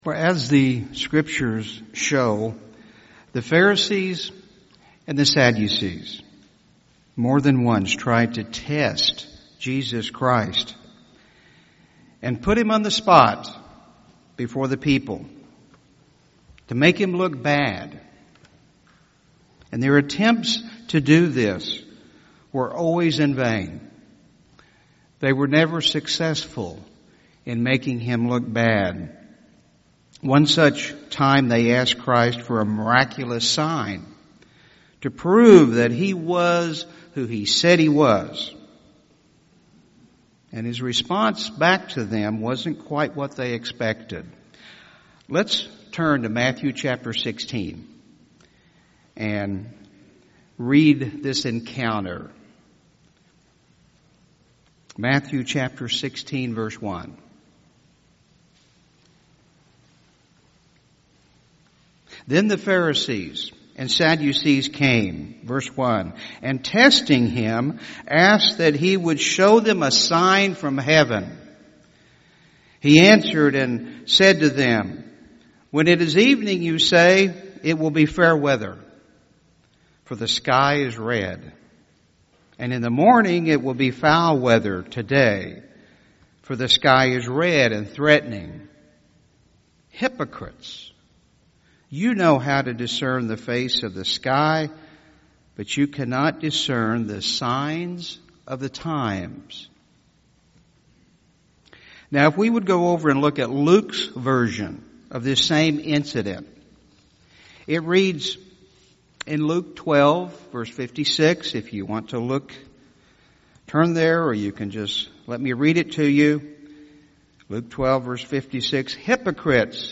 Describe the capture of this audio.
Given in Tulsa, OK